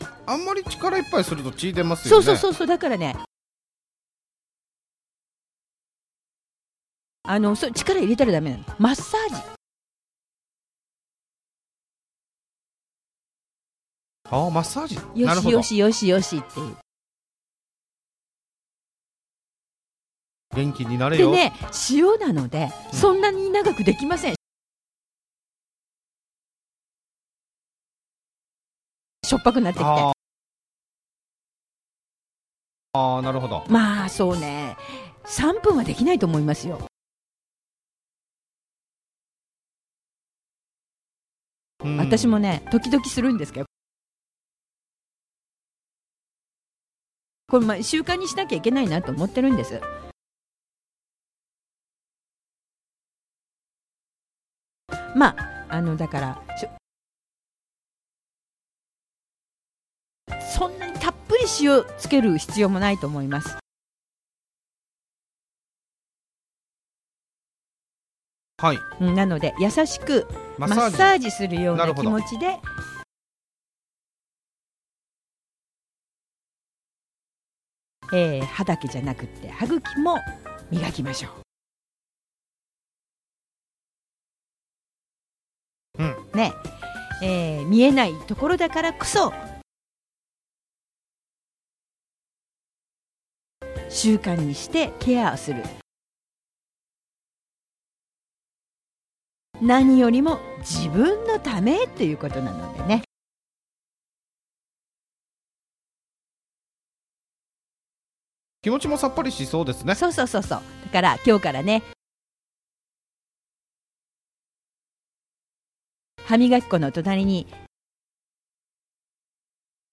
Natural Speed
Natural Speed with Pauses